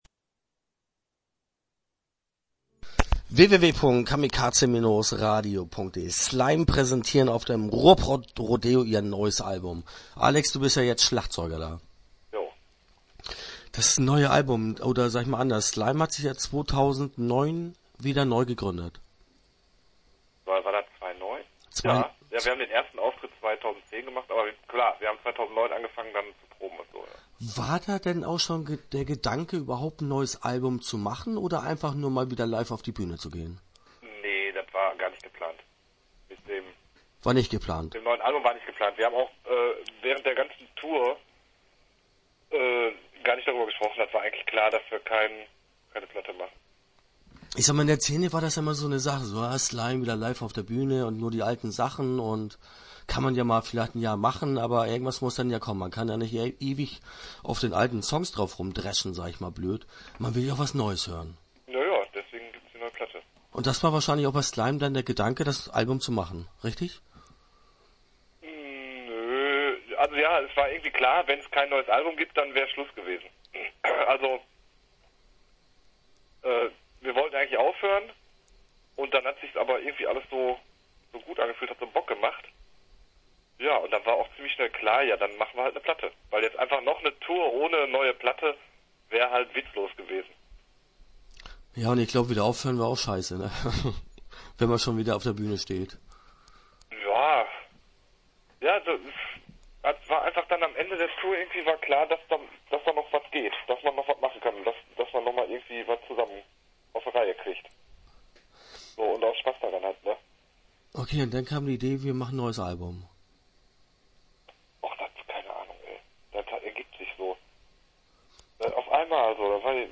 Start » Interviews » Slime